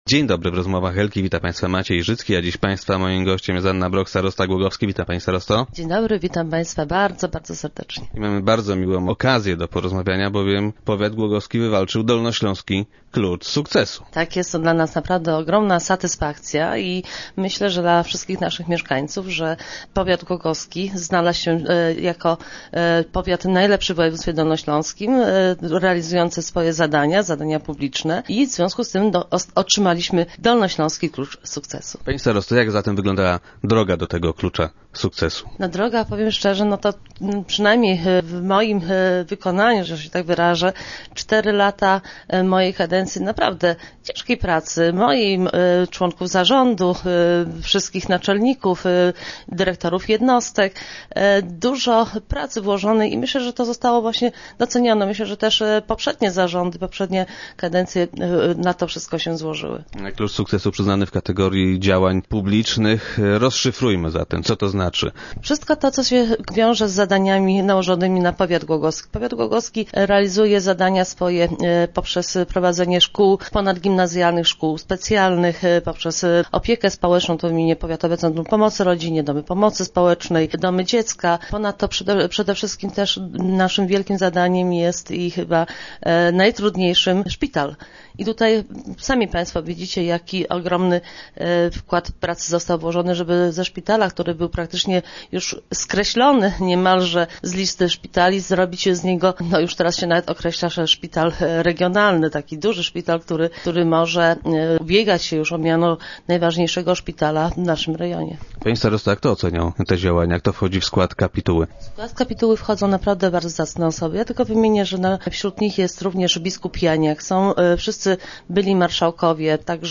- Myślę, że jest to doskonałe podsumowanie tej kadencji - powiedziała nam starosta Anna Brok, która była dziś gościem Rozmów Elki.
- Jest to dla nas ogromna satysfakcja, że to właśnie nasz powiat okazał się tym najlepszym powiatem na Dolnym Śląsku - powiedziała na radiowej antenie starosta.